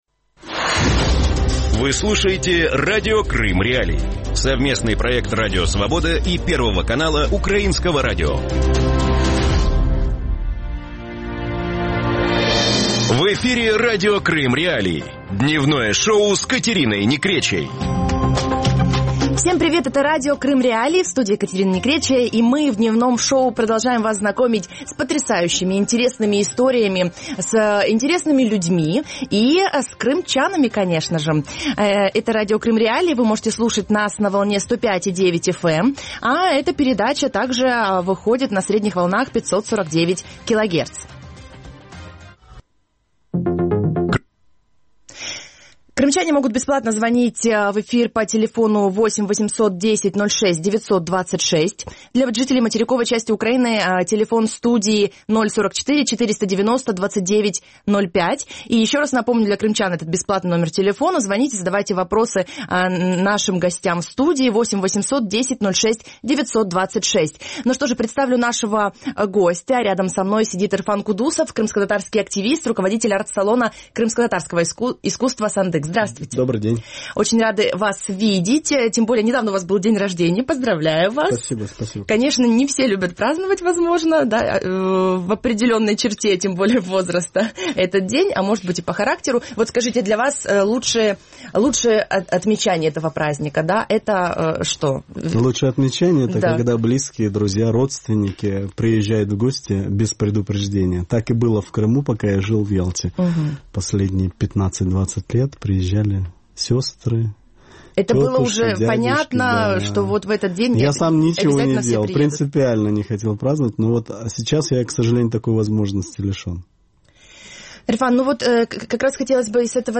Гость студии: крымскотатарский активист